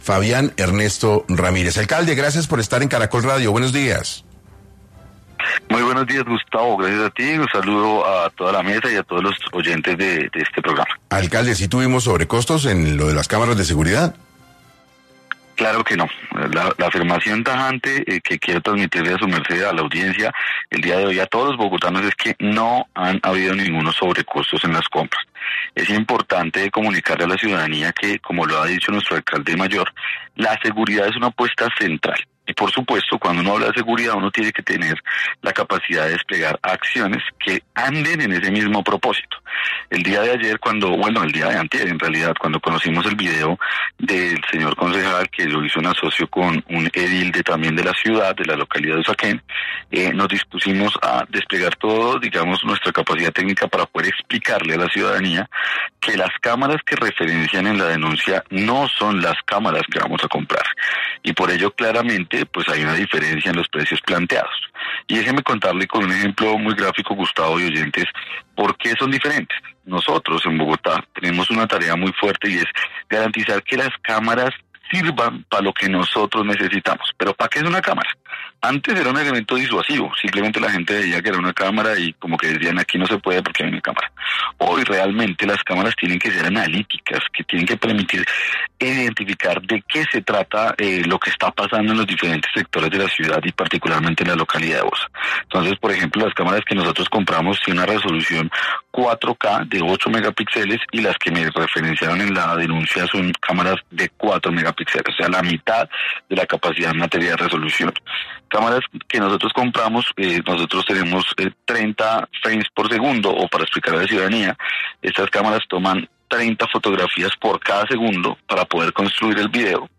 Fabián Ernesto Ramírez, alcalde local de Bosa responde al concejal Daniel Briceño sobre supuestos precios inflados en la compra de kits de seguridad.